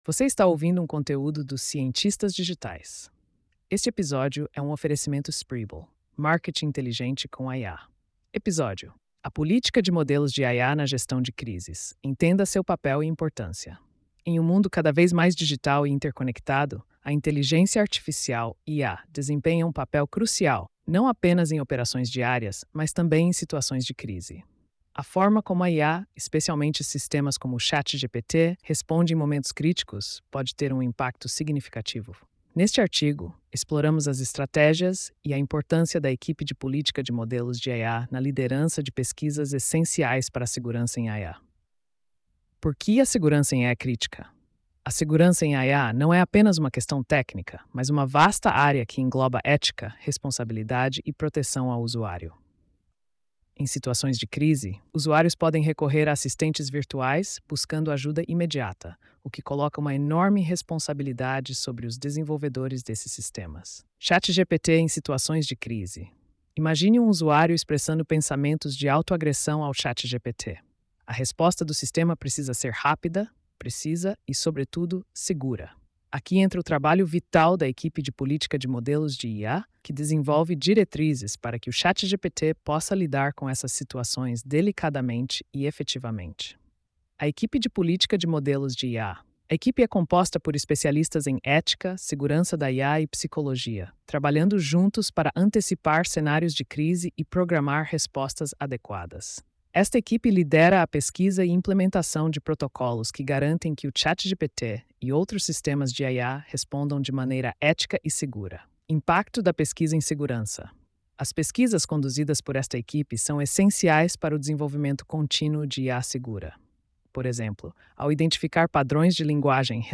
post-4553-tts.mp3